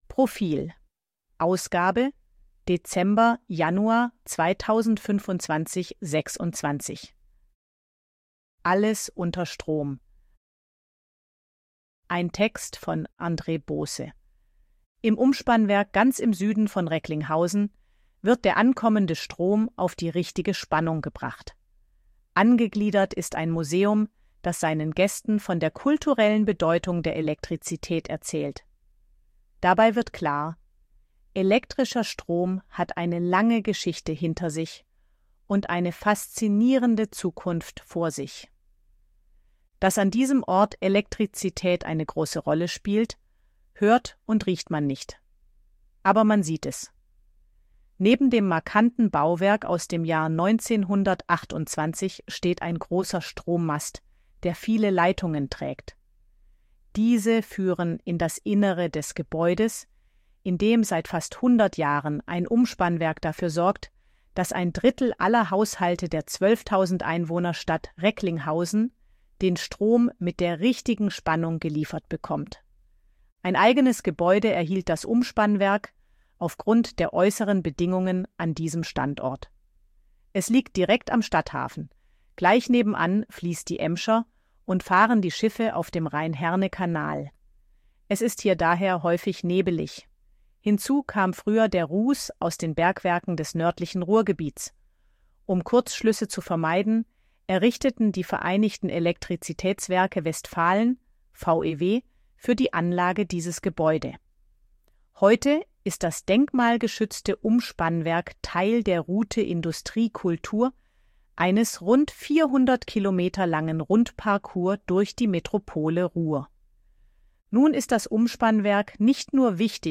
Artikel von KI vorlesen lassen
ElevenLabs_256_KI_Stimme_Frau_Betriebsausflug.ogg